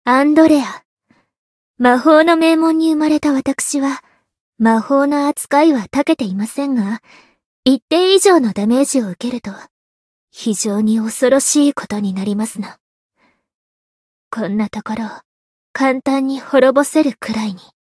灵魂潮汐-安德莉亚-人偶初识语音.ogg